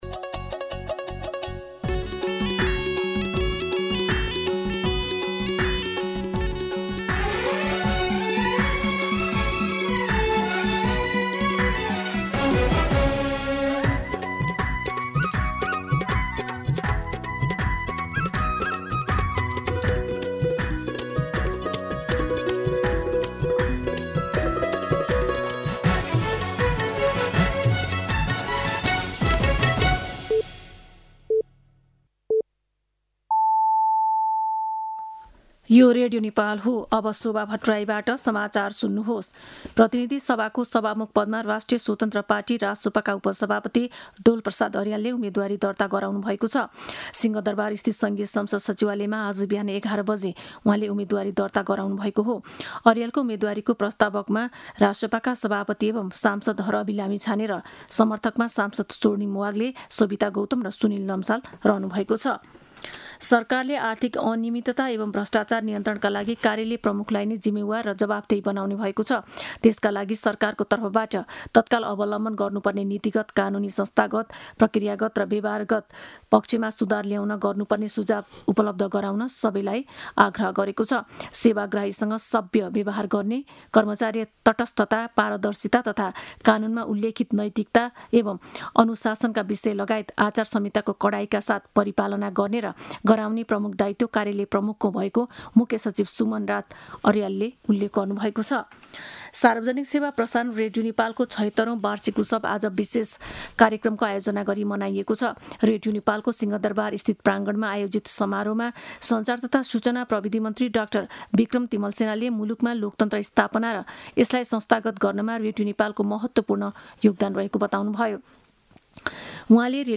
दिउँसो ४ बजेको नेपाली समाचार : २० चैत , २०८२